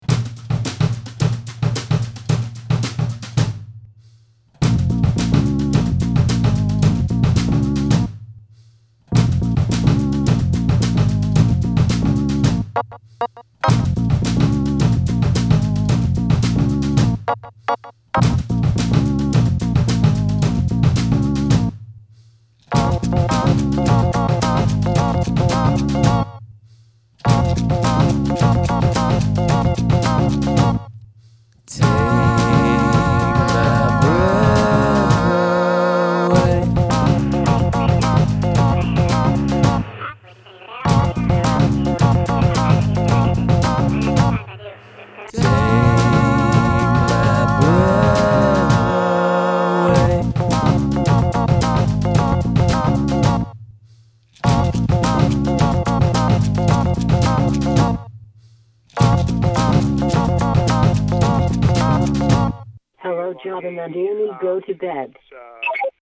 Some songs are mostly beats and keys.